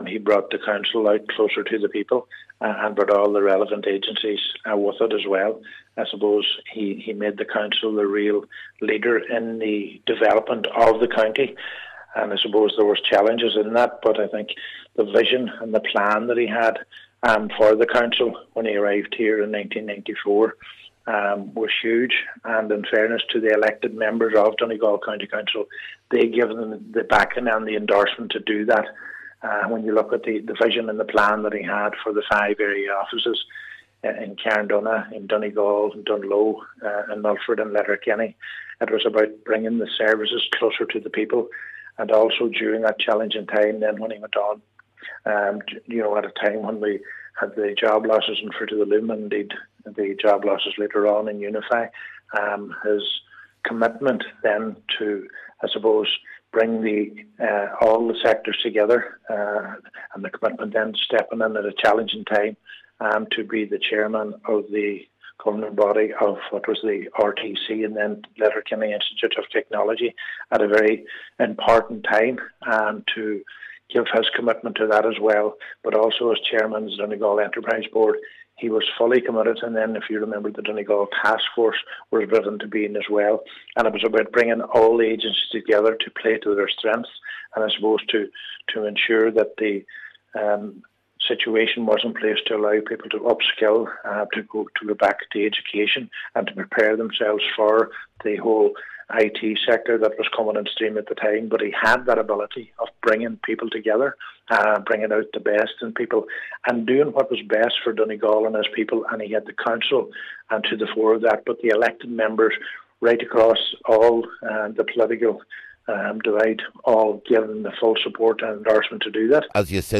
He says Michael McLoone transformed Donegal County Council……….